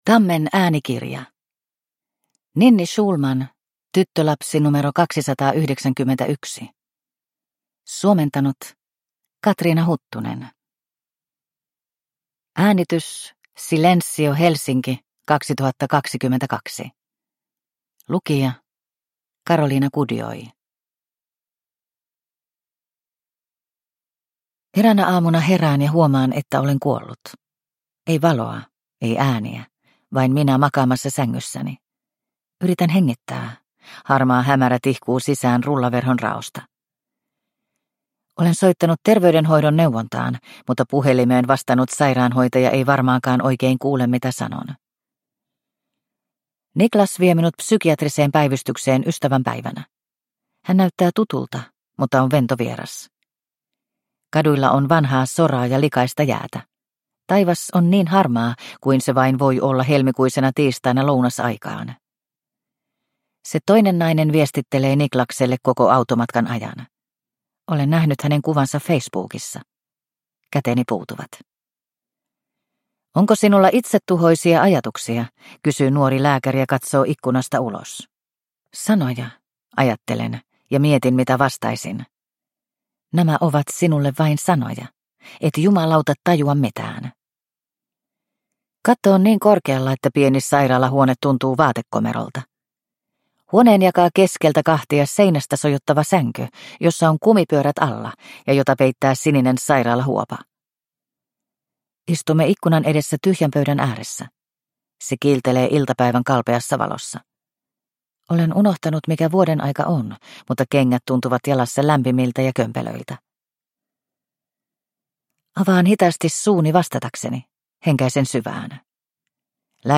Tyttölapsi nro 291 – Ljudbok – Laddas ner